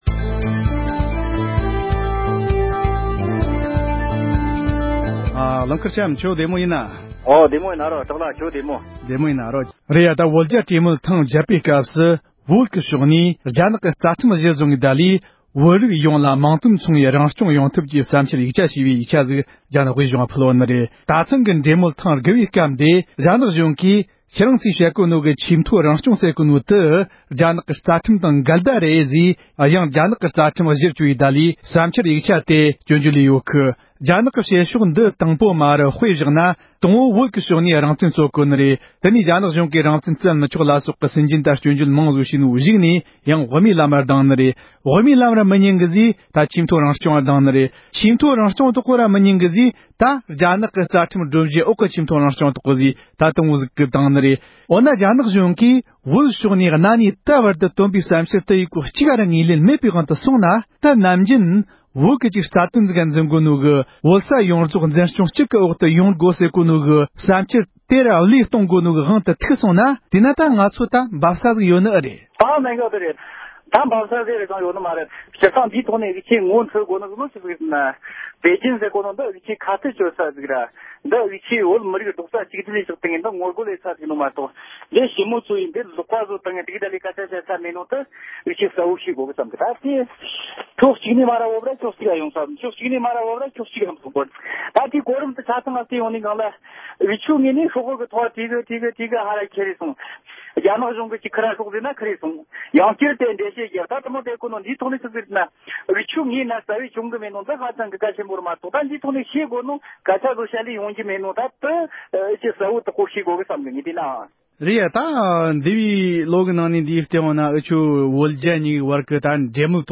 བོད་ཕྱོགས་ནས་ད་བར་དུ་བོད་དོན་ཐག་གཅོད་བྱེད་རྒྱུའི་ཐབས་ལམ་ཞིག་རྙེད་ཡོད་མེད་ཐད་བགྲོ་གླེང་གཉིས་པ།